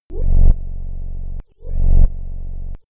Royalty free sounds: Signals